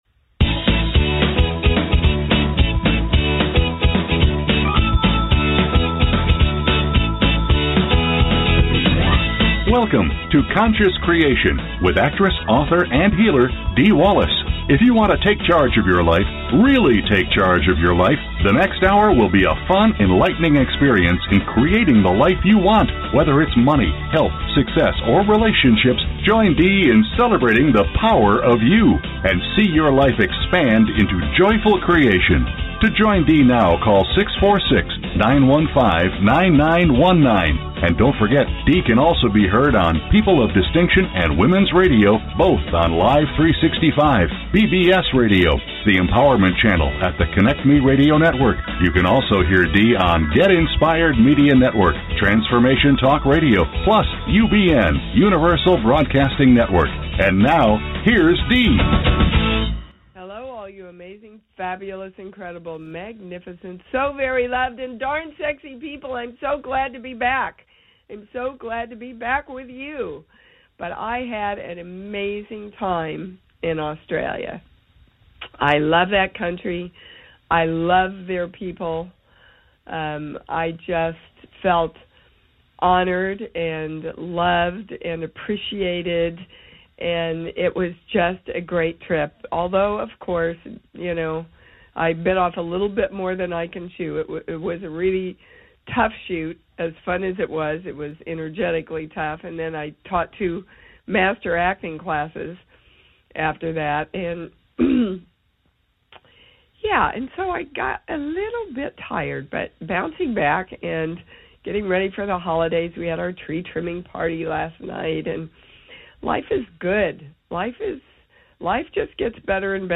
Talk Show Episode, Audio Podcast, Conscious Creation and with Dee Wallace on , show guests , about conscious creation,Dee Wallace,Core Truths,Balanced Life,Energy Shifts,Spirituality,Spiritual Archaeologist,Core Issues,Spiritual Memoir,Healing Words, categorized as Health & Lifestyle,Kids & Family,Philosophy,Psychology,Self Help,Spiritual,Access Consciousness,Medium & Channeling